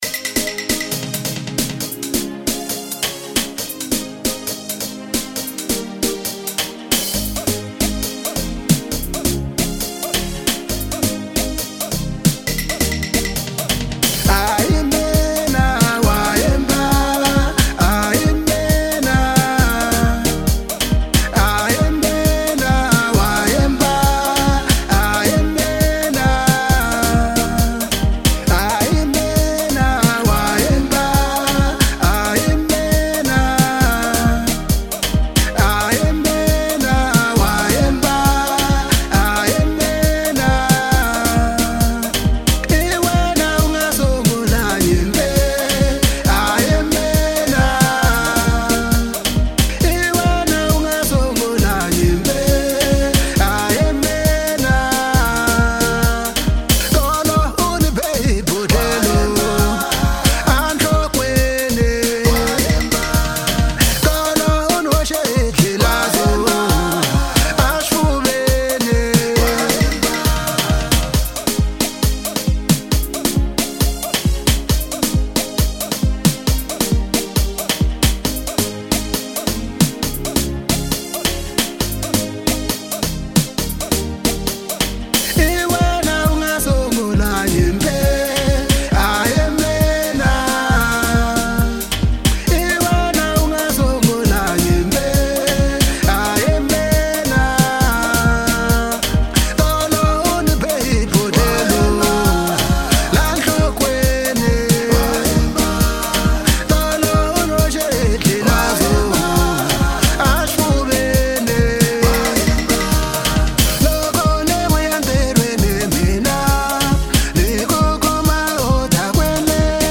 04:41 Genre : African Disco Size